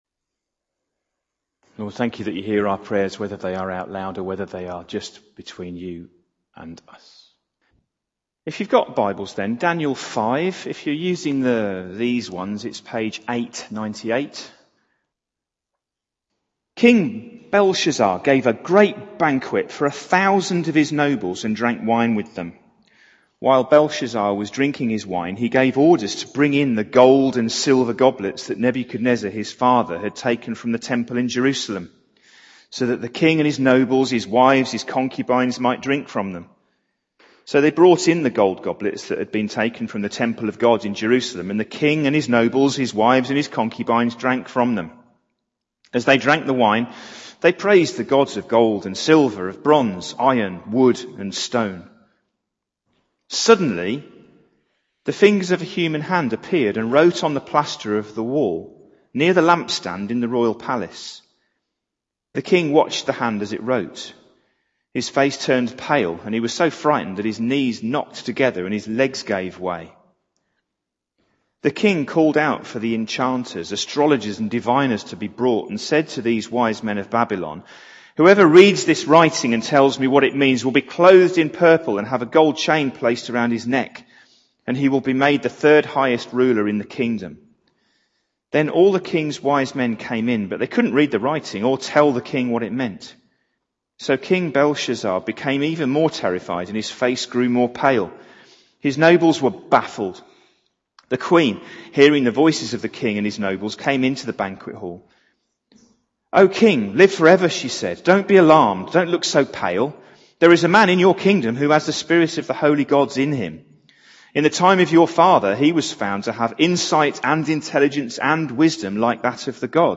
Jun 30, 2019 Night-time Encounters: Part 3 MP3 SUBSCRIBE on iTunes(Podcast) Notes Sermons in this Series In Daniel 5 the writing is on the wall, what can this teach us about how God may speak to us if we choose to let him speak through the mundane?